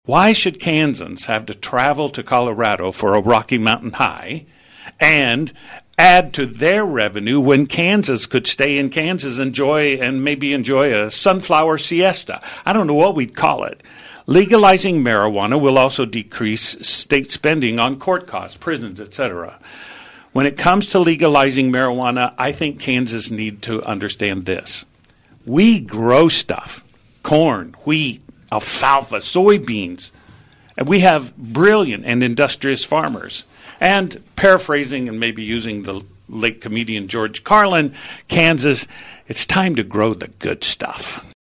The candidates for the Kansas House 60th District position clashed on several topics during KVOE’s final Candidate Forum of this general election cycle.